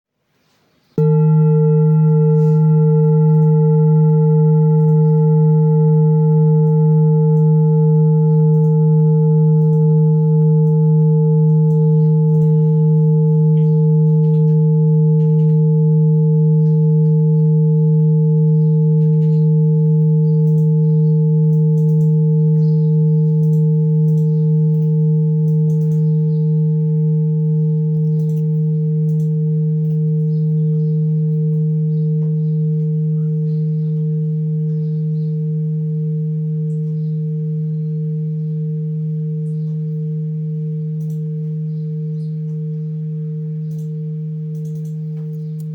Jambati Singing Bowl, Buddhist Hand Beaten, with Fine Etching Carving
Material Seven Bronze Metal
It can discharge an exceptionally low dependable tone.